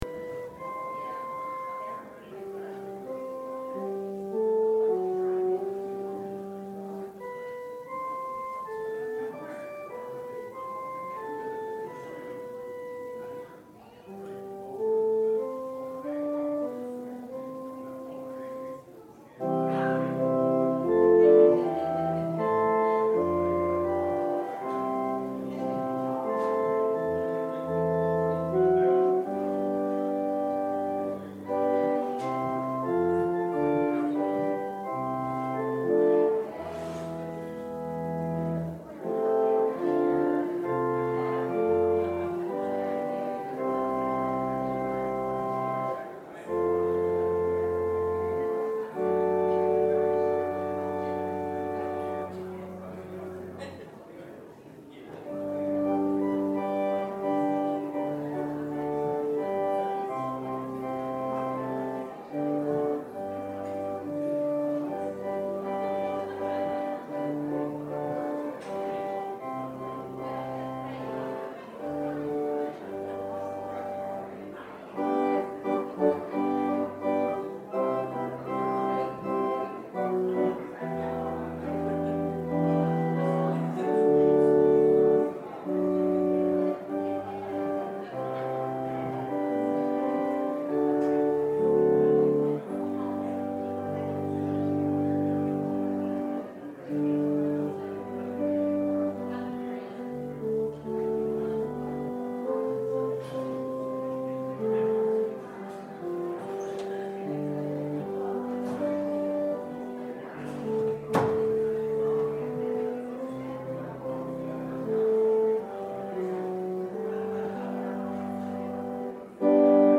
Matthew 14:22-36 Service Type: Sunday Worship Topics